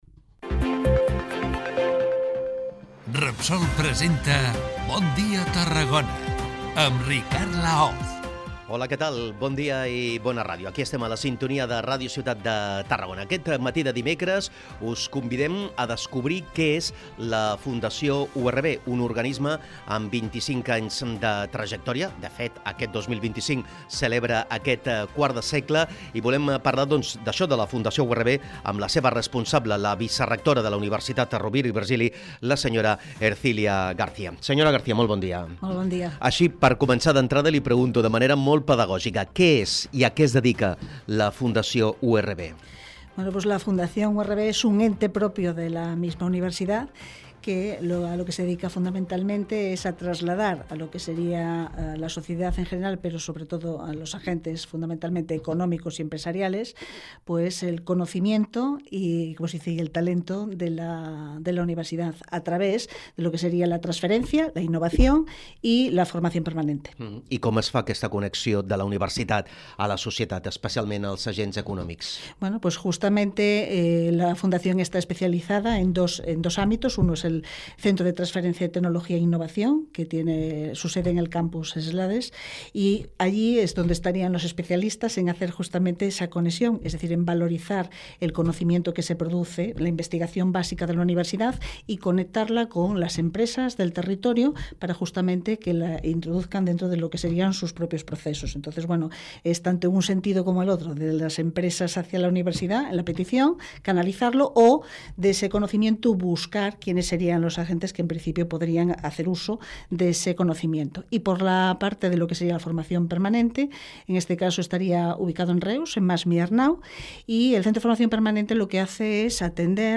Aquestes i altres preguntes en aquesta entrevista MIRA I ESCOLTA AQUÍ L’ENTREVISTA ÍNTEGRA